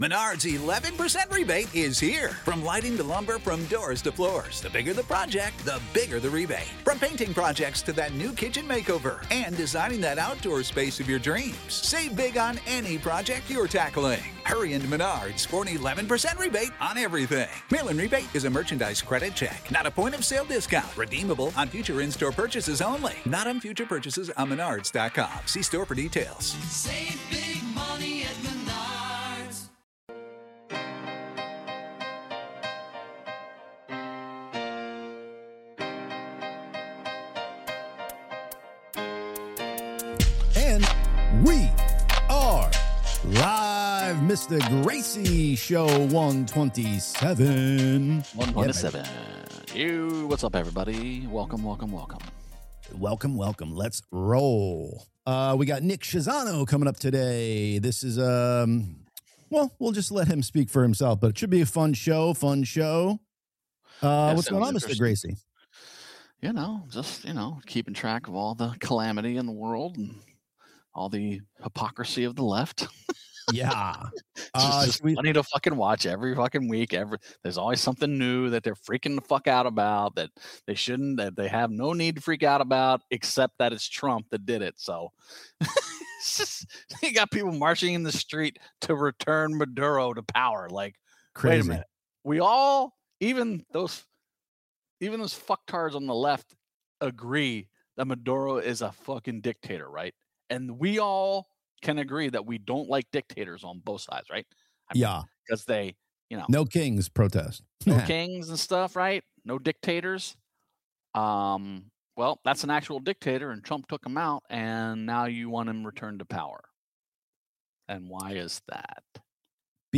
The tone is conversational, profane, partisan, and mixing news/opinion with casual internet culture clipsThe Gracious Two LIVE Podcast - Every Tuesday at 1 pm EASTERN...